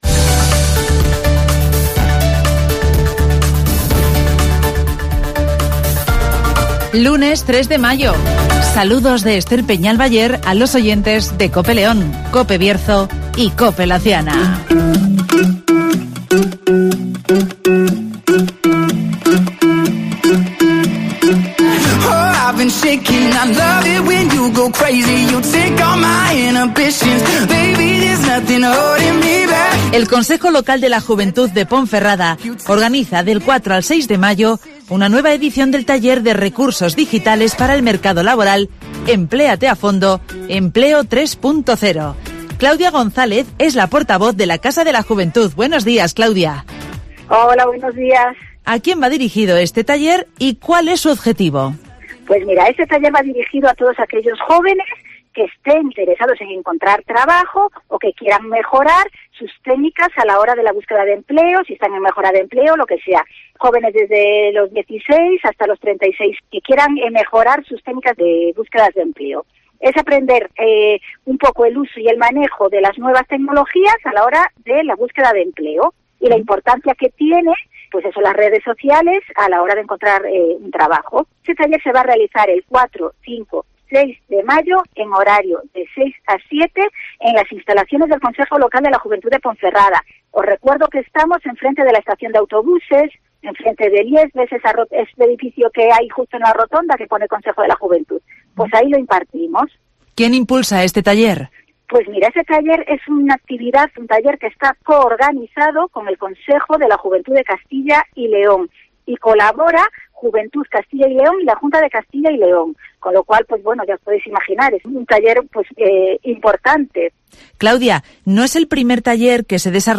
El Consejo de la Juventud de Ponferrada organiza un nuevo taller de recursos digitales para buscar empleo (Entrevista